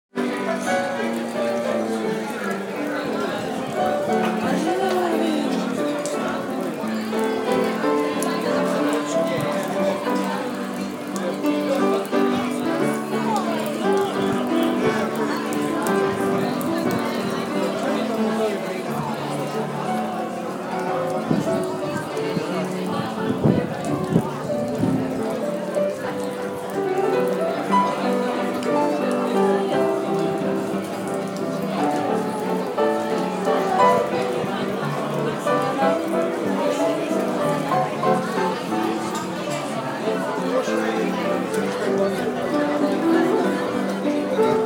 Dibrovnik Restaurant in rain